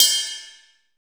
HARDRIDEBEL.wav